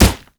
punch_grit_wet_impact_01.wav